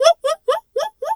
zebra_whinny_12.wav